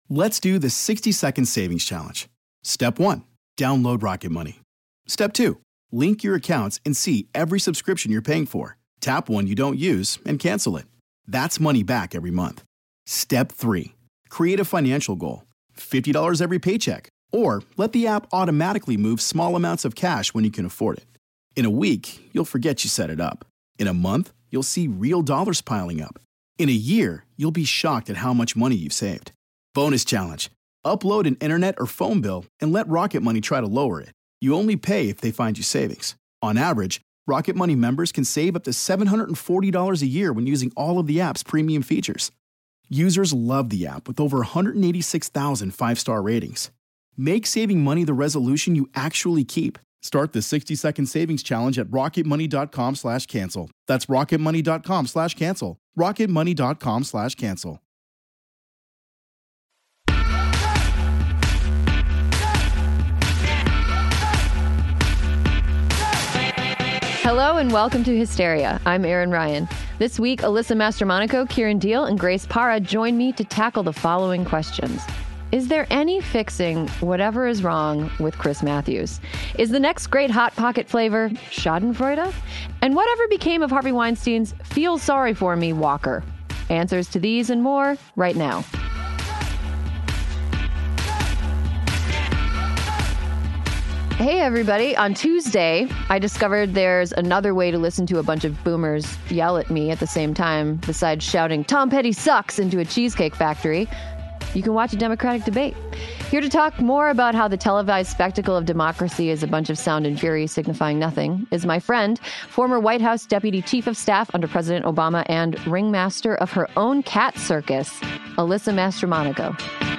join in-studio to parse out their feelings after the Weinstein verdict.